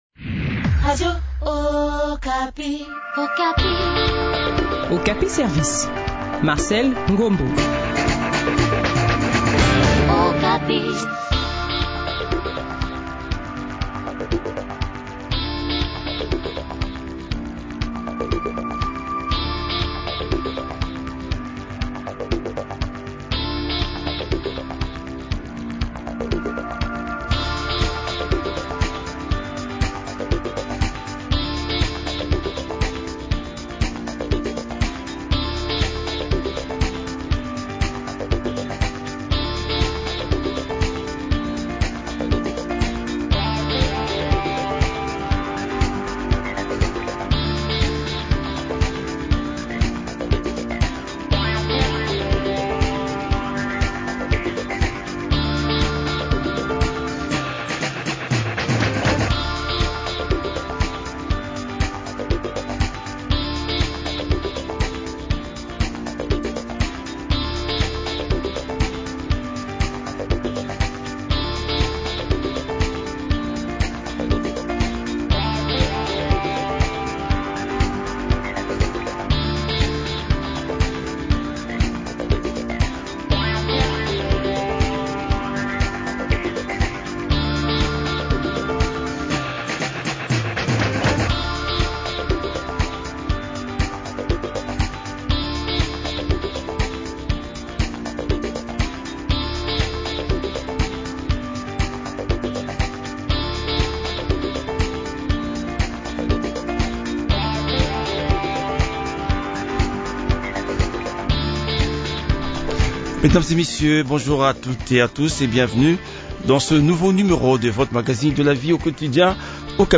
expert en créativité et innovation.